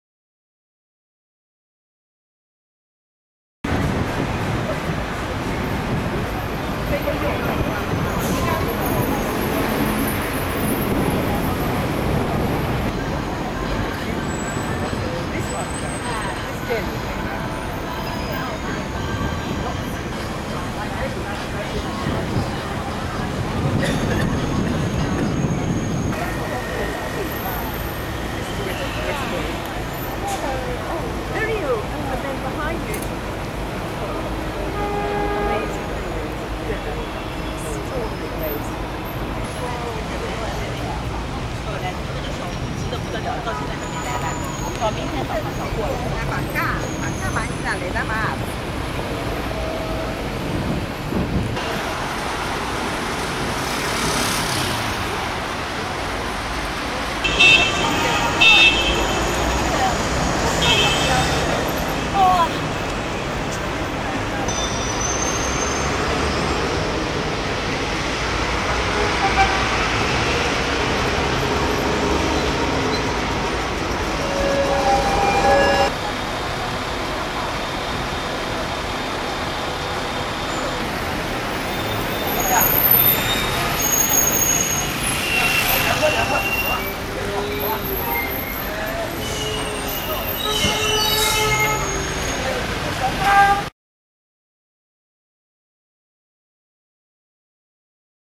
812. Nanjing Street, Shanghai 2003